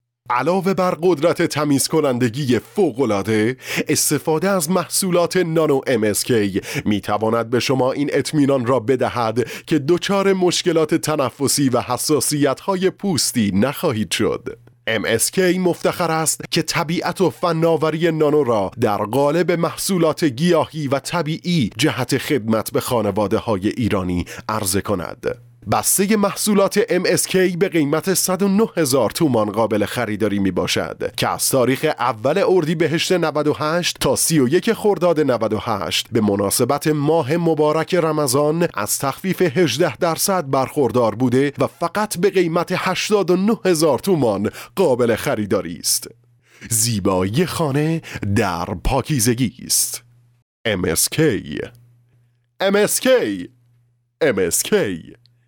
Male
Adult
Narration